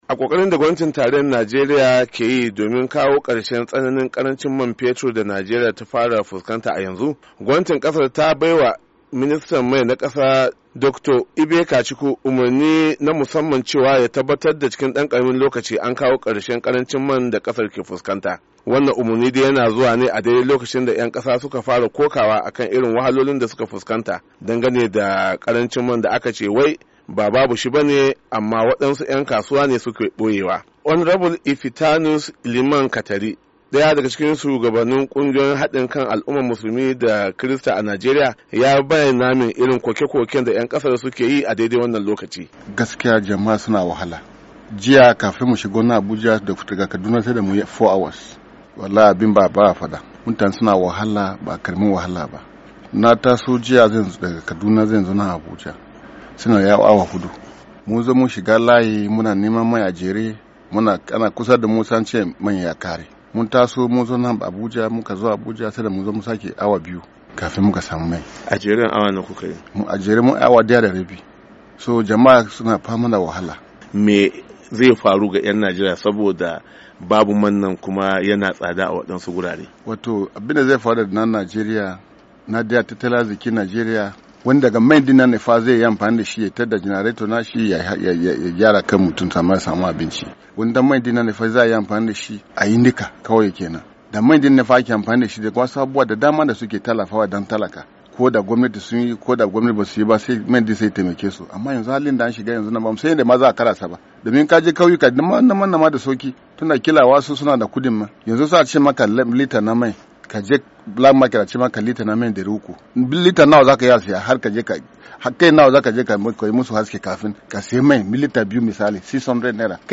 WASHINGTON DC —